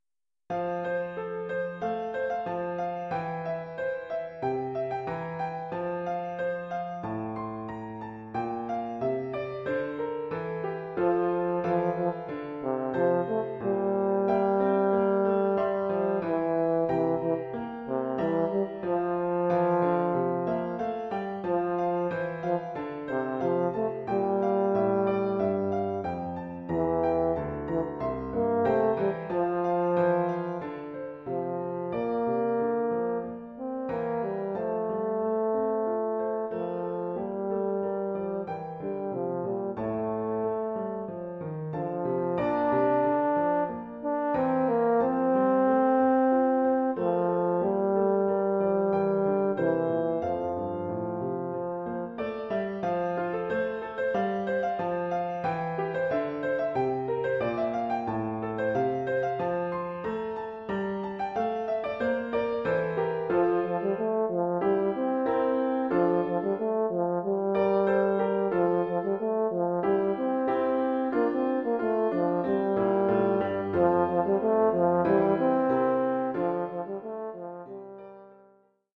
Formule instrumentale : Cor et piano
Oeuvre pour cor d’harmonie et piano.